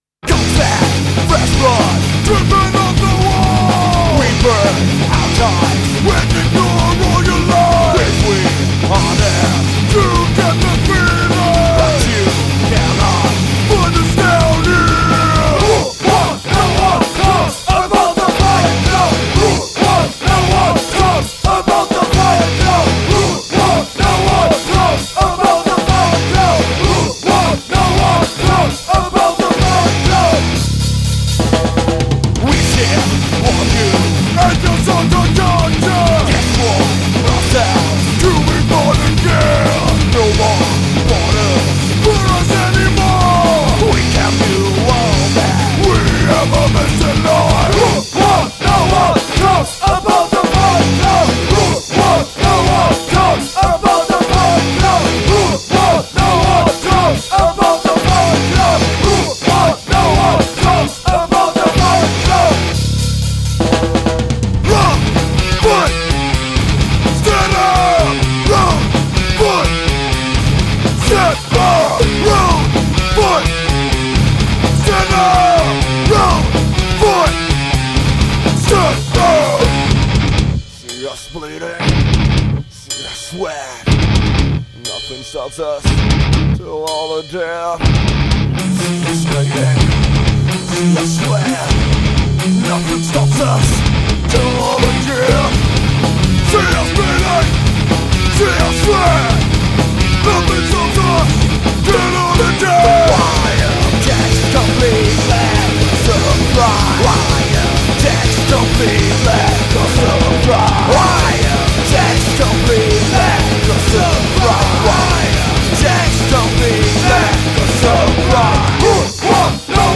Vocals
6-Strings
4-Strings / Noise
Drums